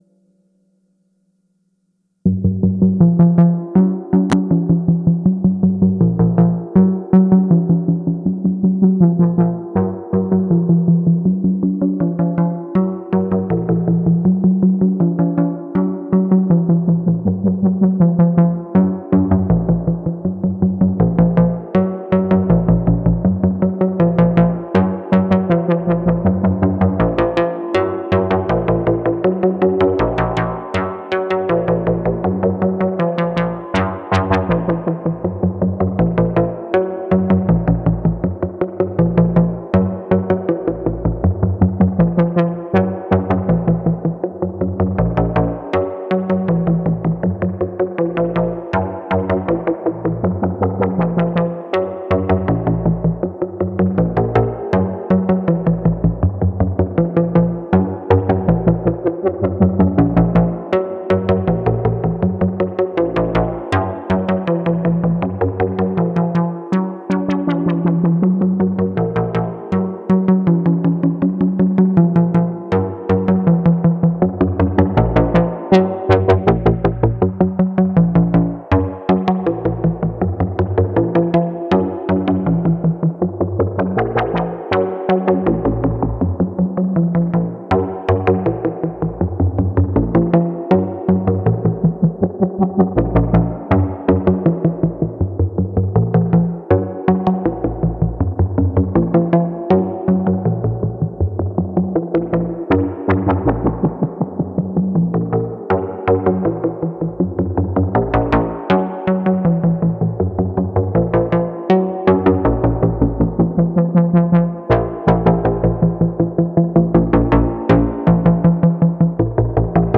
Ring mod.